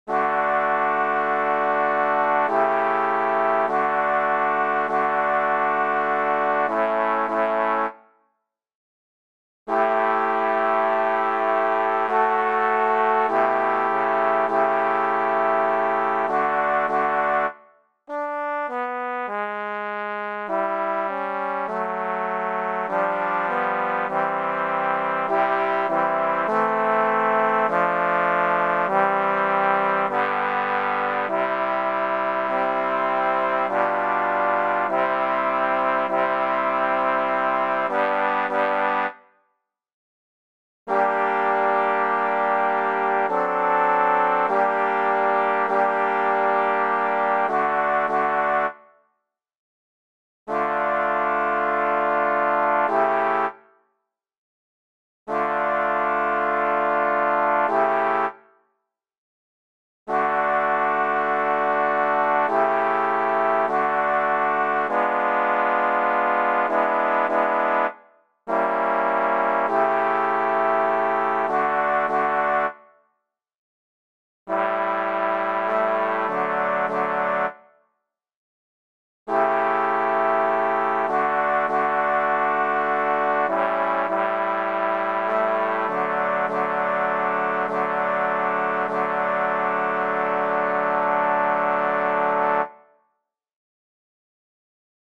the sampled trombone in the audio files is slightly wobbly on some notes
Drei_Equale_no2_12TET_SmplTromb.mp3